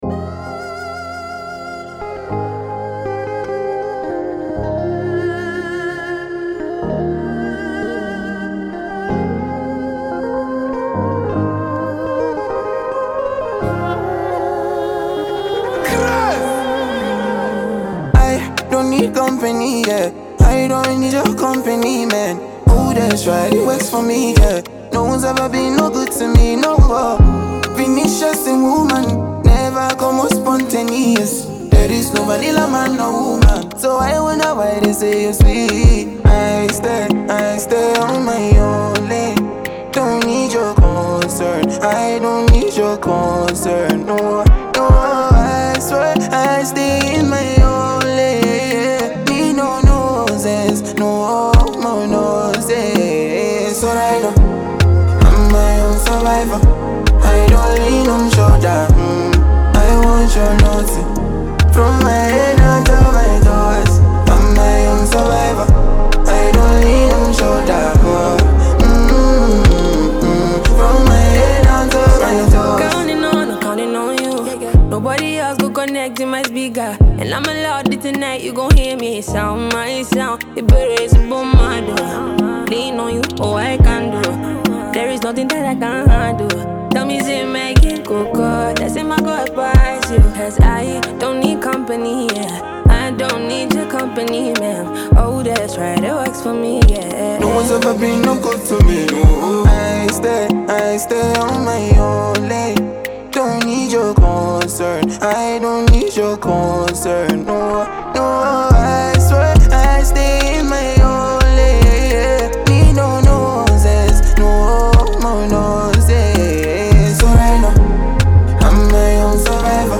emotional banger
this Ghanaian Afrobeat track hits deep.
a moody, polished beat
soulful vocals